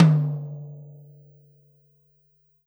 Tom Shard 09.wav